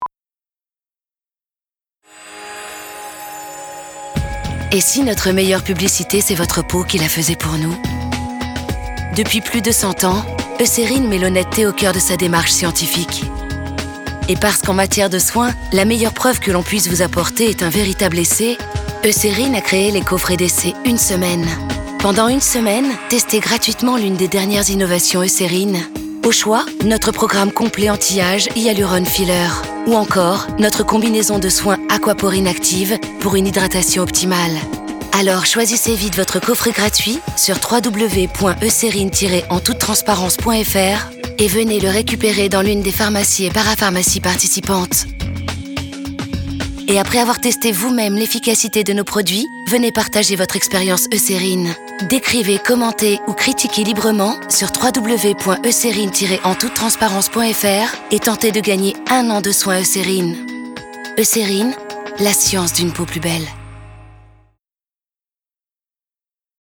Comédienne, voix off, voix méduim, naturelle et dynamique, Publicitès, Voices Over ou institutionnels
Sprechprobe: Industrie (Muttersprache):
Female Voice, Méduim, Smiling, natural and dynamic! Advertising, Game, Voice Over and more...since 18 years!